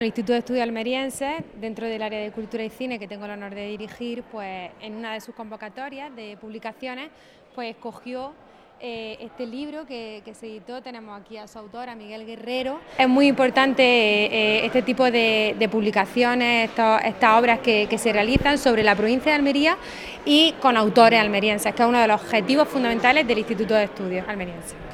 29-06_libro_diputada.wav